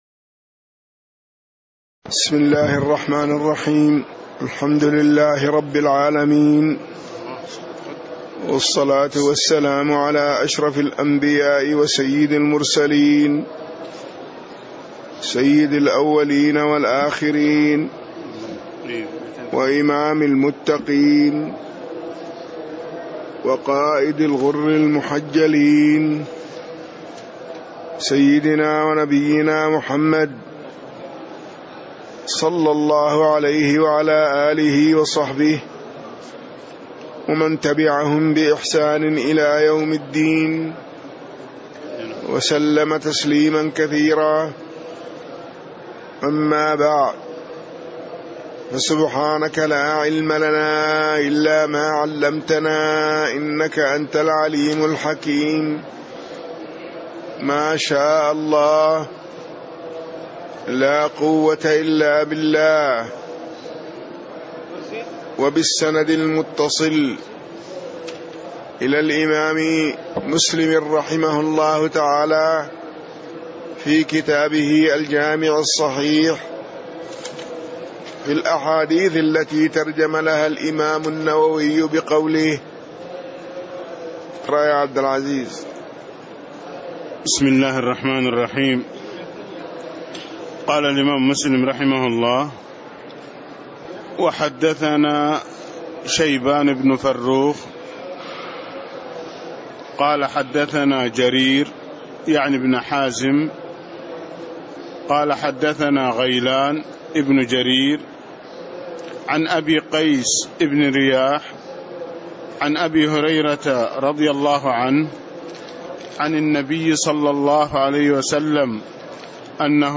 تاريخ النشر ٦ ربيع الأول ١٤٣٦ هـ المكان: المسجد النبوي الشيخ